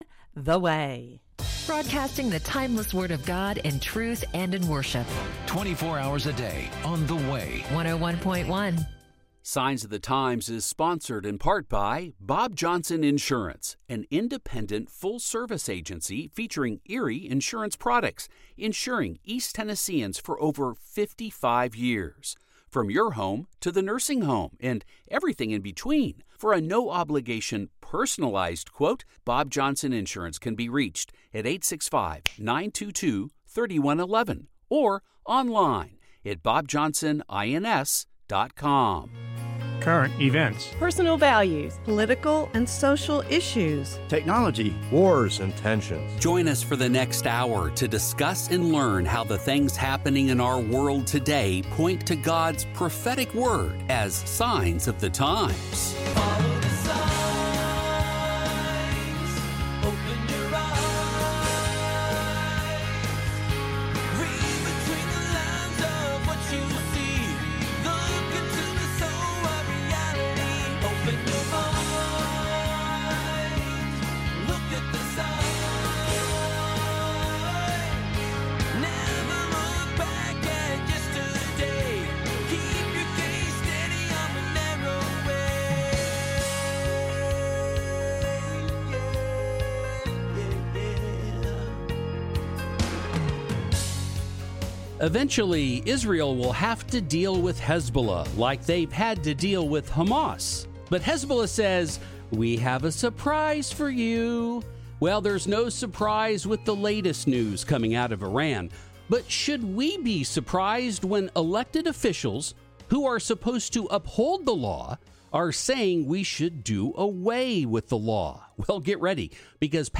Now and Later download sermon mp3 download sermon notes Welcome to Calvary Chapel Knoxville!